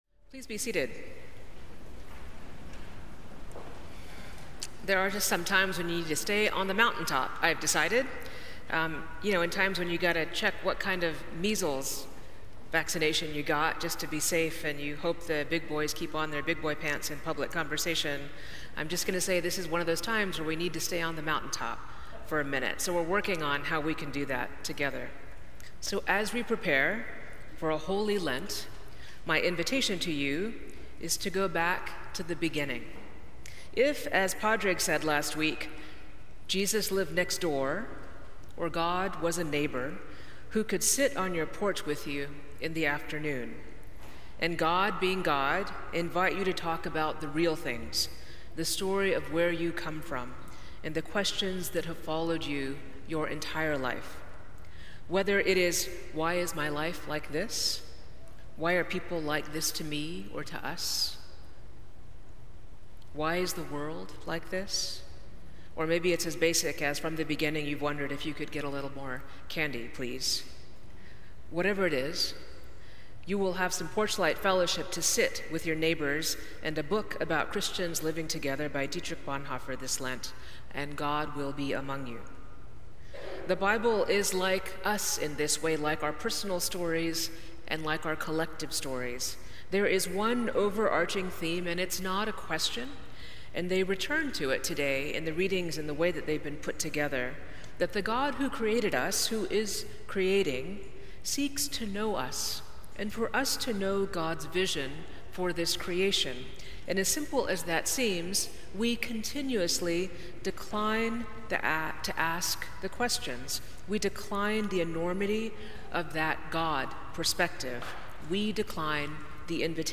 Sermons from St. Luke's Episcopal Church in Atlanta
Sunday, March 2, 2025 Sermon.mp3